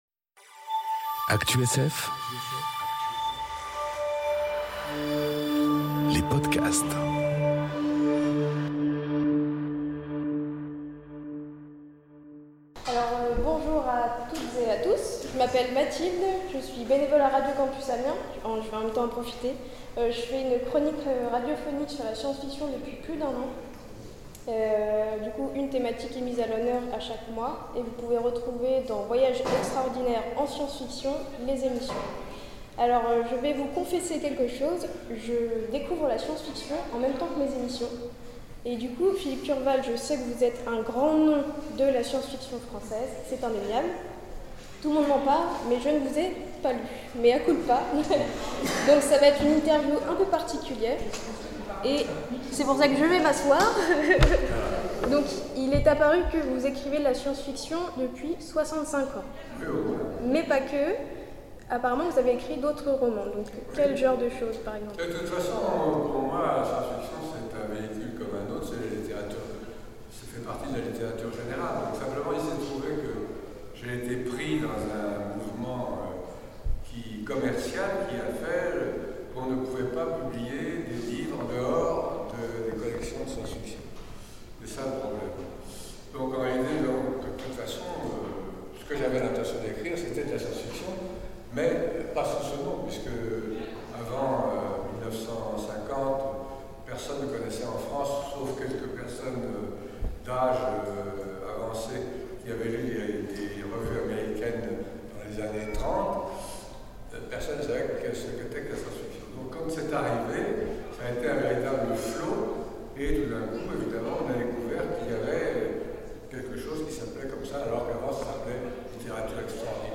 Eurocon 2018 : Interview de Philippe Curval